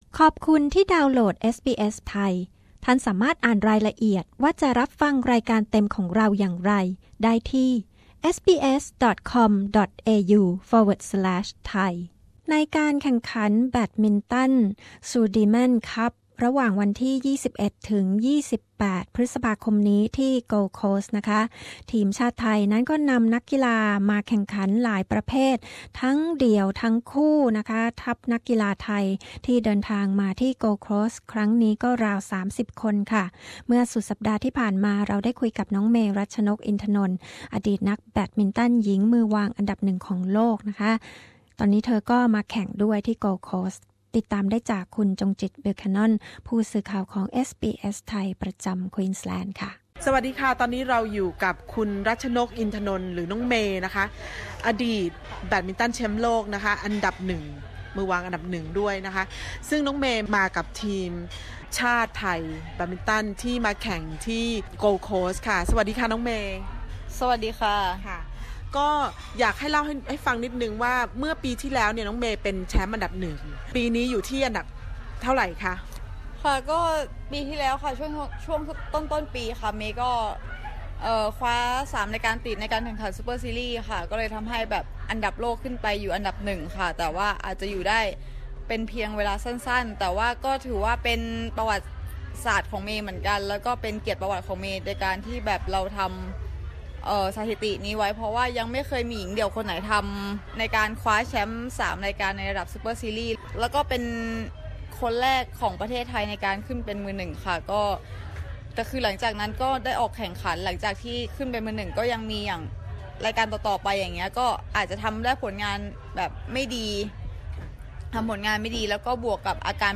SBS Thai chatted to Ratchanok Intanon , the first Thai badminton player who became No.1 in Women's Singles, while she is competing in Sudirman Cup in Gold Coast.